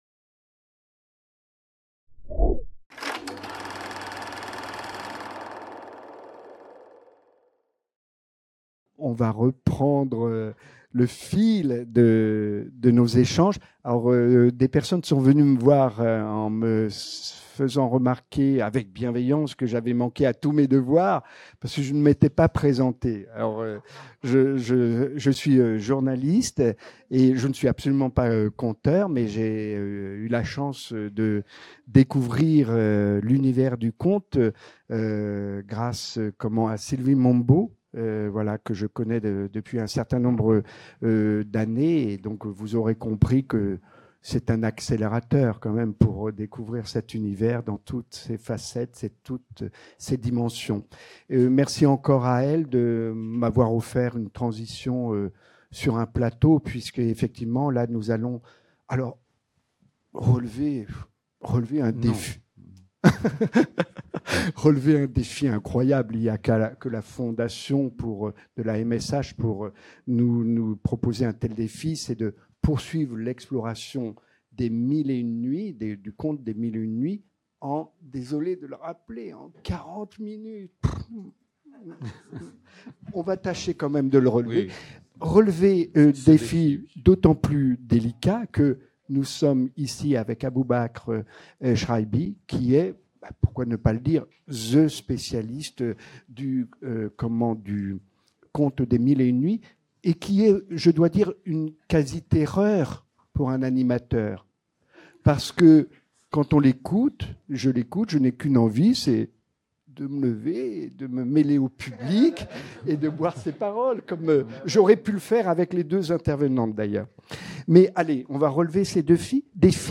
Deuxième partie de la rencontre à la FMSH, pour explorer le pouvoir des contes, à l'occasion de la nuit blanche parisienne, qui s'est tenue le 7 juin 2025